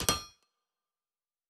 Blacksmith 02.wav